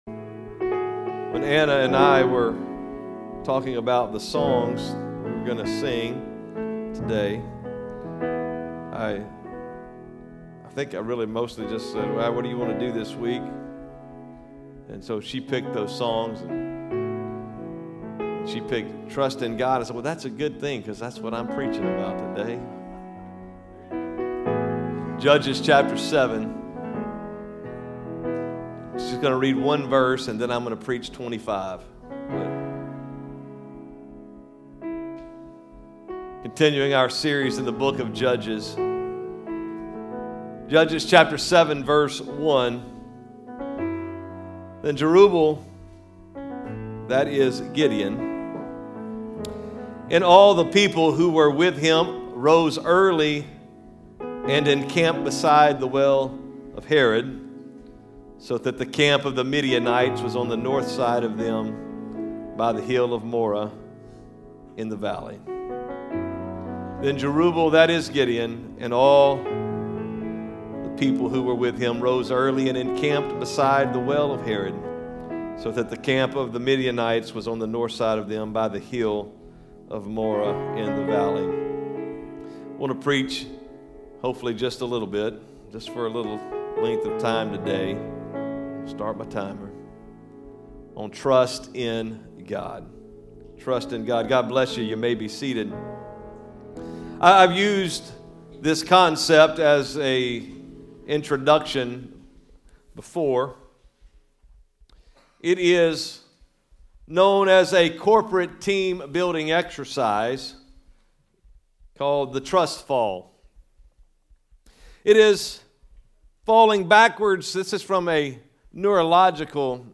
Sermons | Cross Church Kansas City